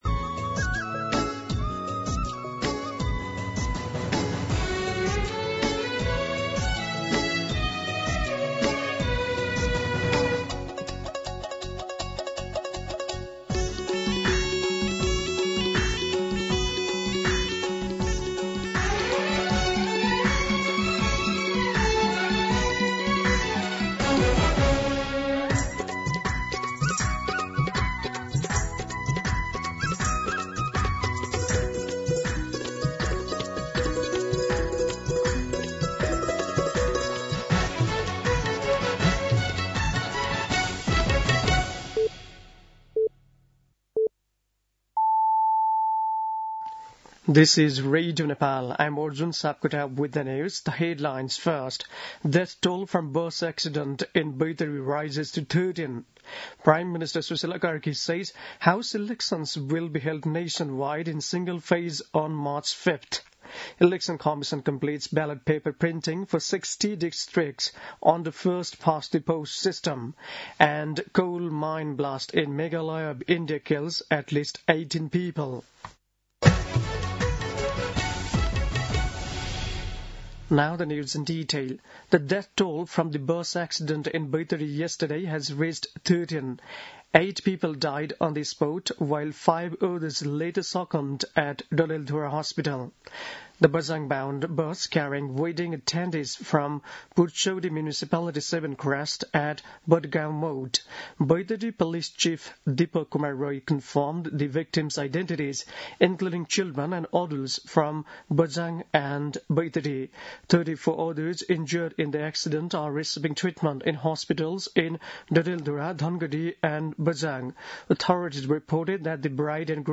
दिउँसो २ बजेको अङ्ग्रेजी समाचार : २३ माघ , २०८२